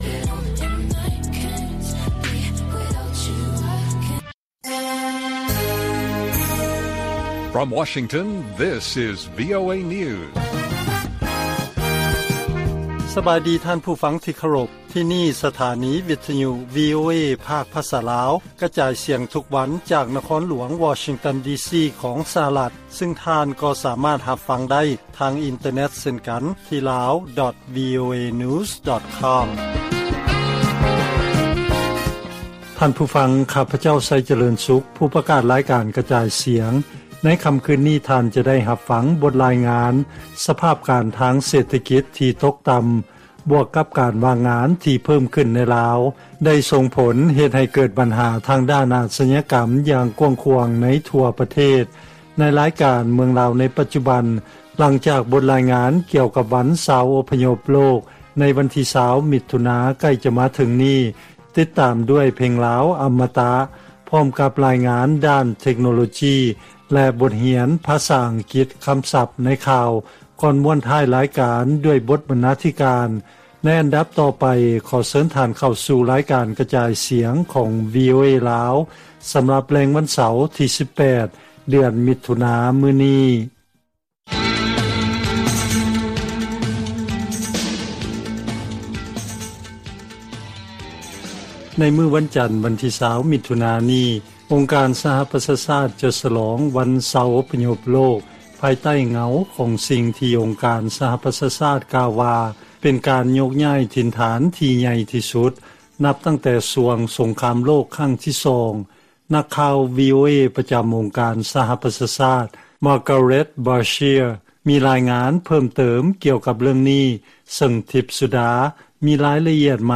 ວີໂອເອພາກພາສາລາວ ກະຈາຍສຽງທຸກໆວັນ ເປັນເວລາ 30 ນາທີ.